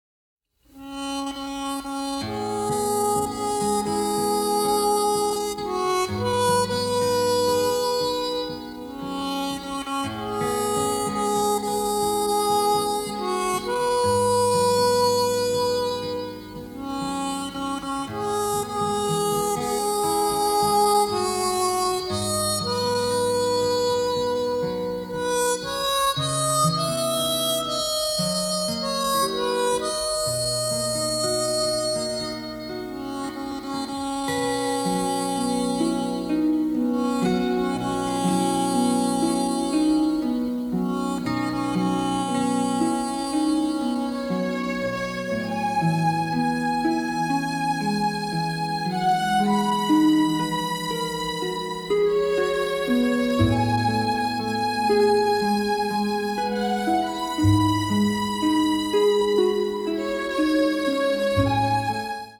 composed for a large chamber orchestra